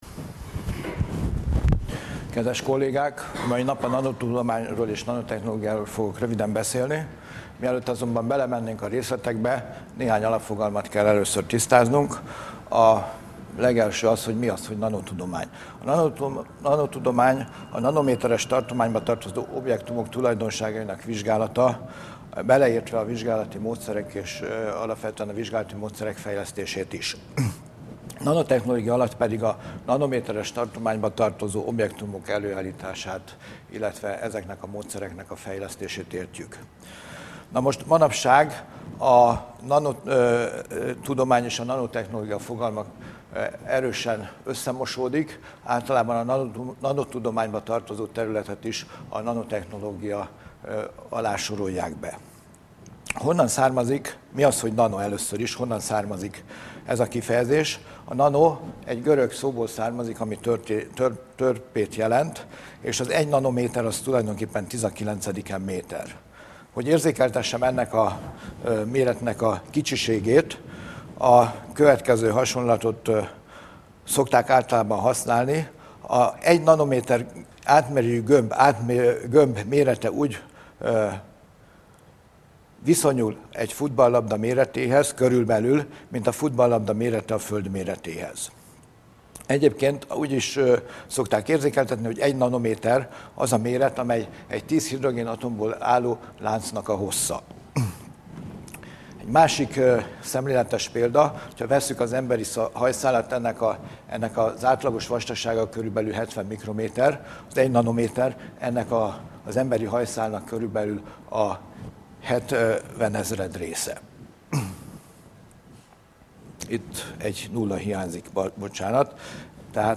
(lecturer)